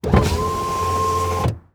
windowpart1.wav